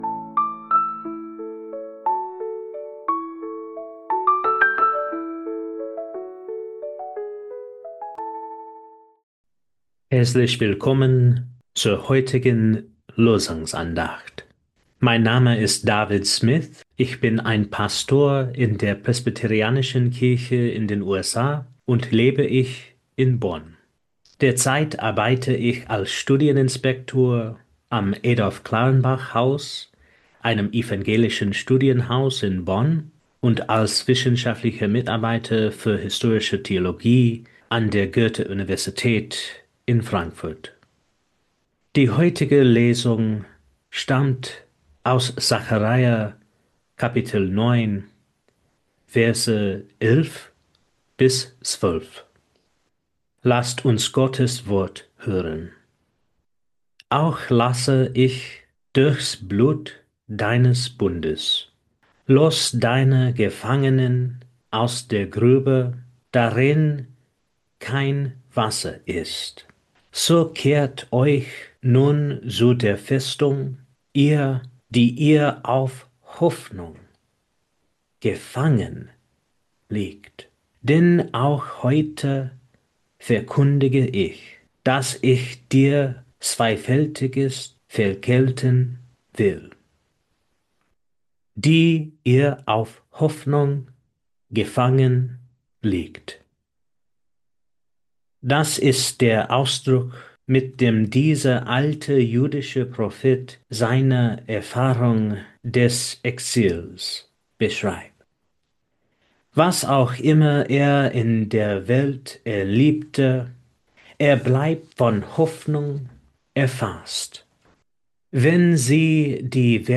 Losungsandacht für Dienstag, 23.09.2025